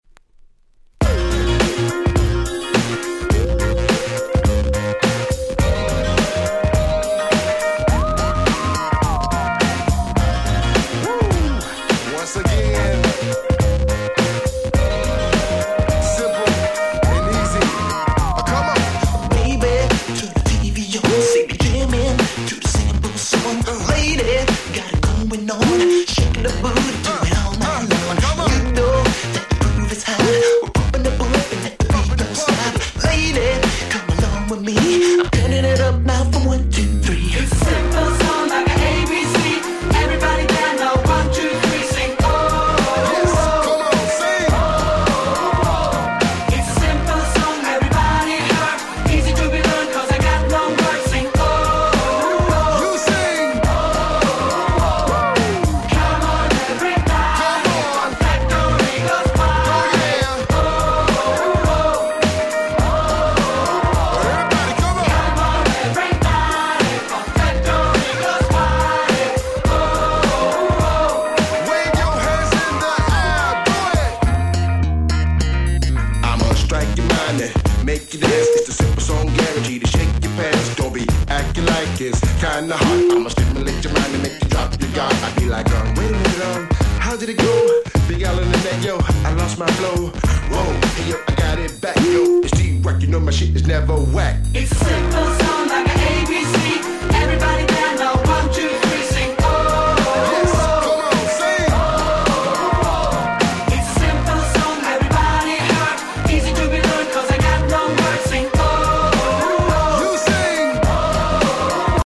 しっかりEuro R&Bしちゃってる何気に凄く良い曲